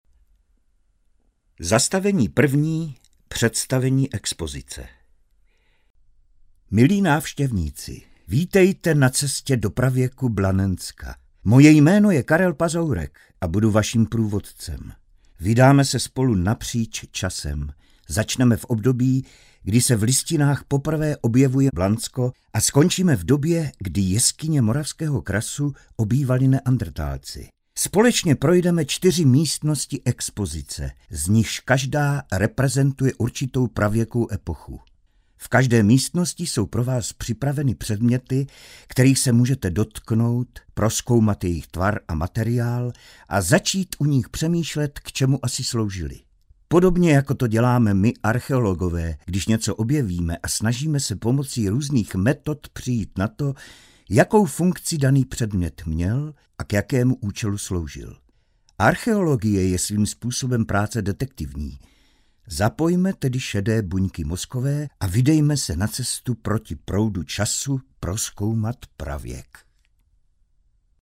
Audio průvodce